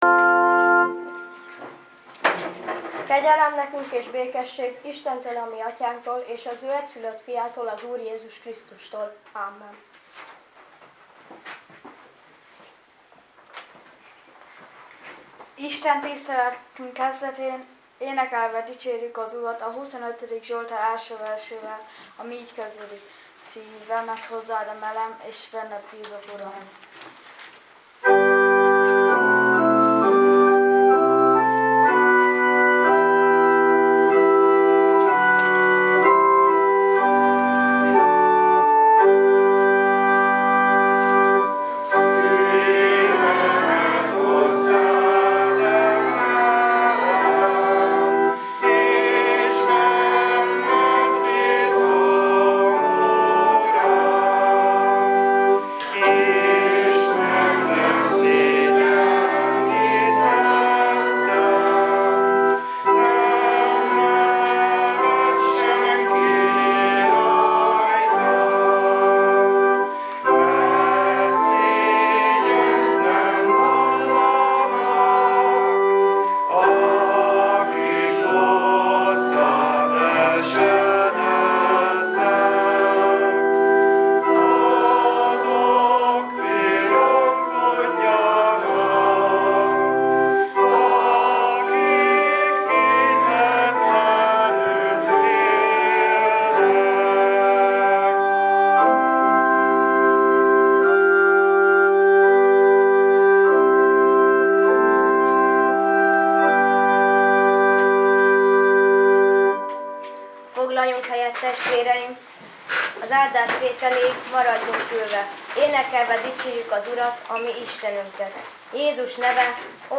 ifjúsági istentisztelet